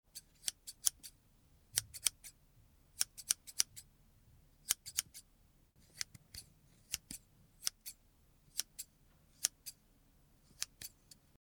Звуки режущих ножниц
Шуршание ножниц в парикмахерской